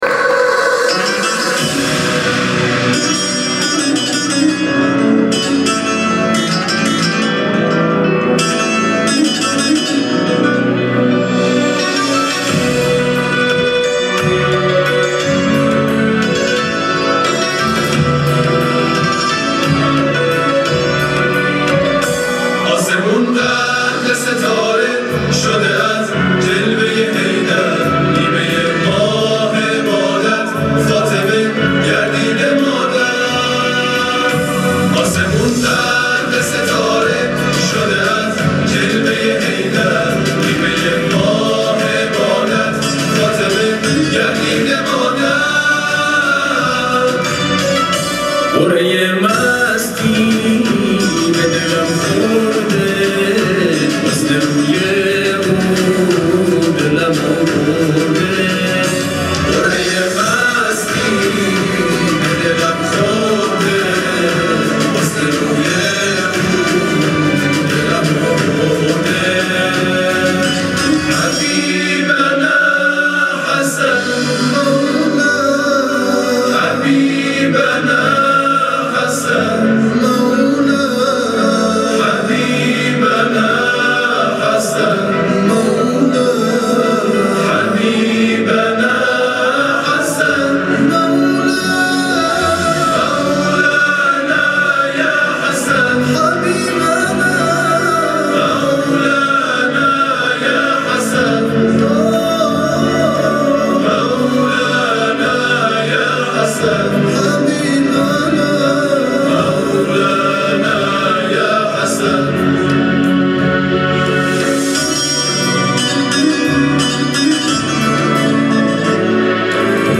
گروه مسابقات: آئین اختتامیه چهارمین دوره مسابقات قرآن سازمان بازنشستگی شهرداری تهران در روز ولادت امام حسن مجتبی(ع) در مرکز همایش‌های برج میلاد برگزار شد.
ابتهال‌خوانی